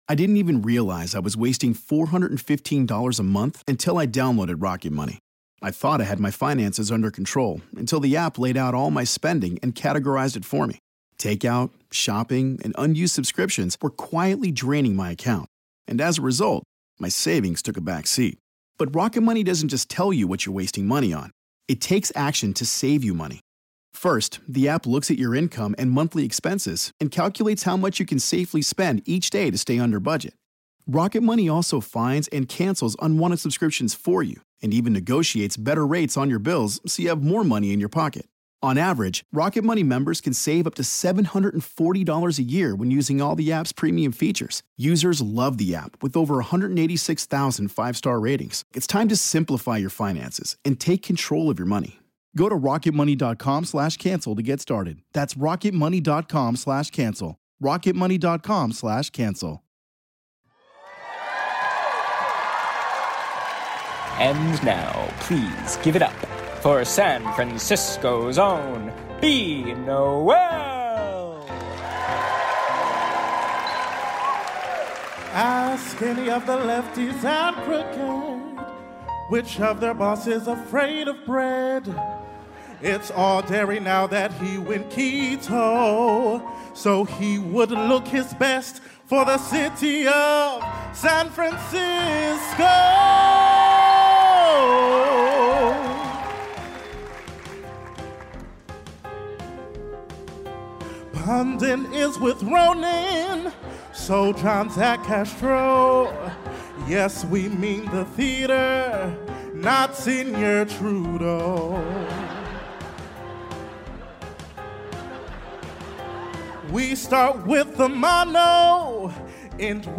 We finally, finally, finally return to touring, and set our clam chowder and sour dough dreams on the beautiful city of San Francisco